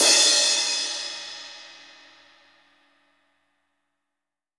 OZ16CRASH1-S.WAV